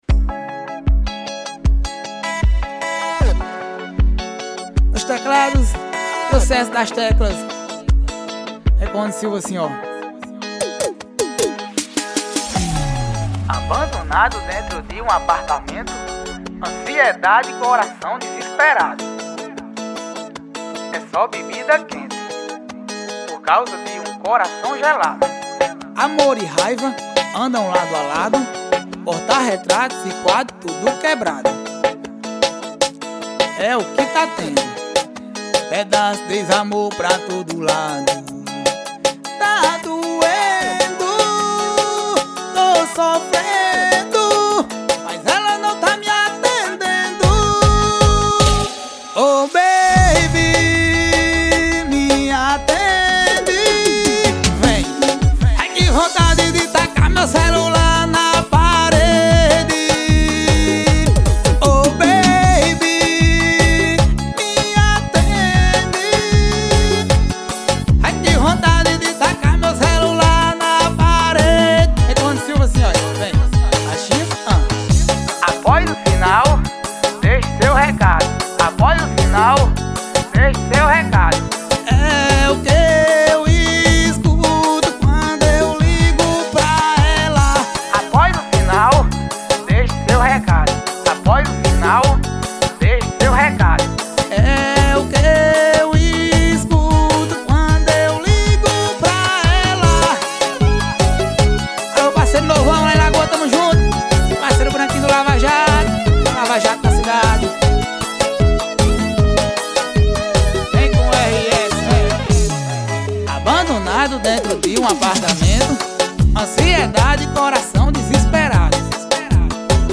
Cover Ao Vivo.